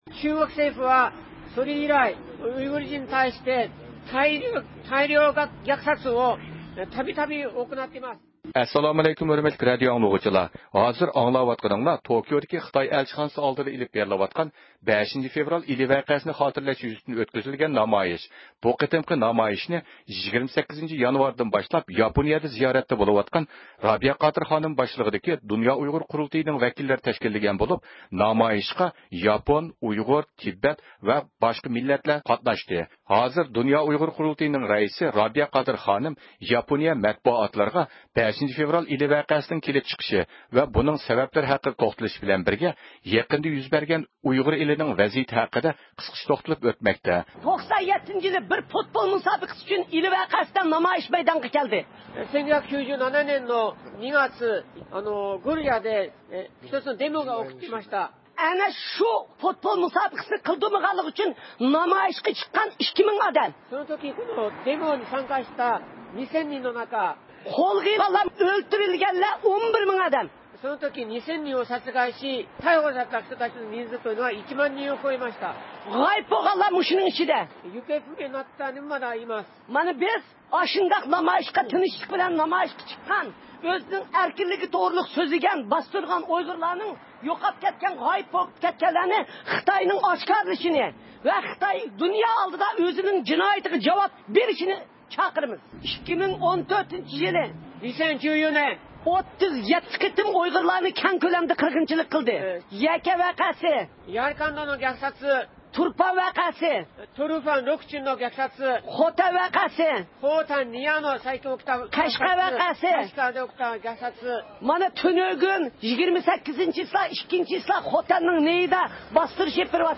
3-فېۋرال كۈنى ياپونىيەدە زىيارەتتە بولۇۋاتقان رابىيە قادىر خانىم باشچىلىقىدىكى دۇنيا ئۇيغۇر قۇرۇلتىيى ۋەكىللىرىنىڭ تەشكىللىشى بىلەن توكيودىكى خىتاي ئەلچىخانىسى ئالدىدا 5-فېۋرالنى خاتىرىلەش يۈزىسىدىن نامايىش ئۆتكۈزۈلدى.